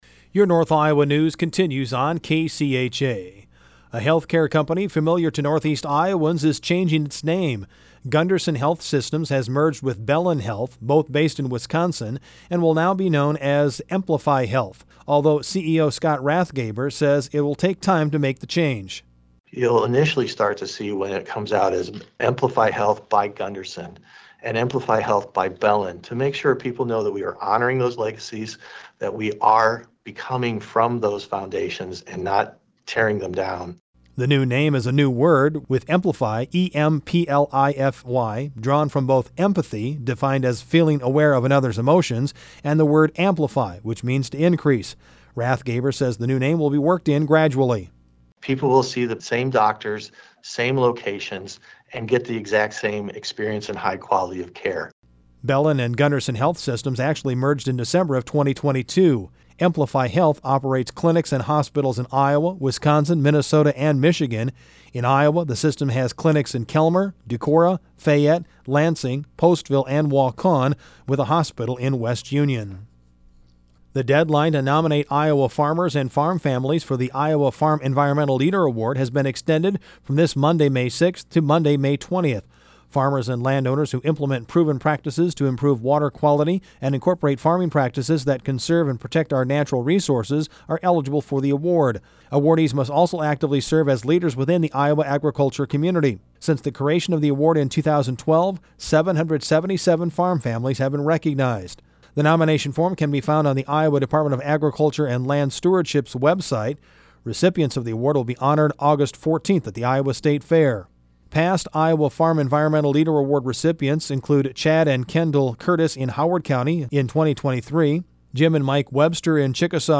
Daily NewscastNews